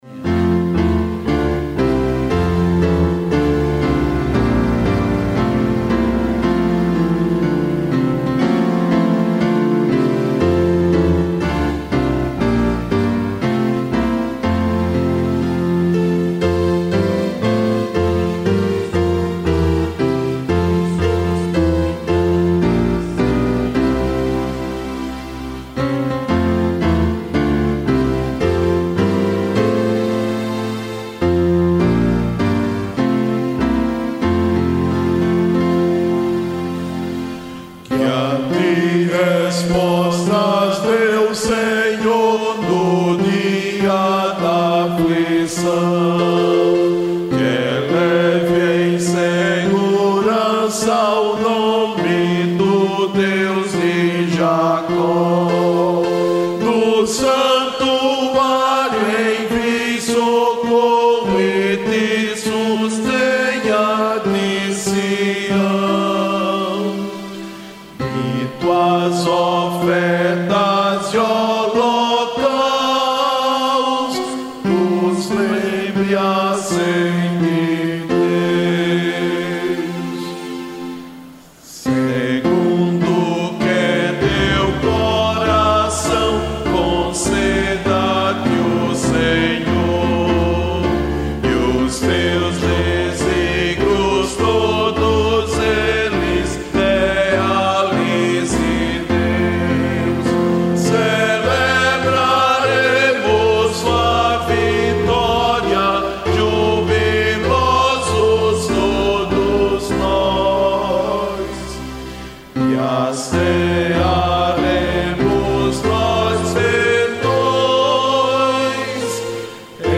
Melodia indonésia
salmo_20B_cantado.mp3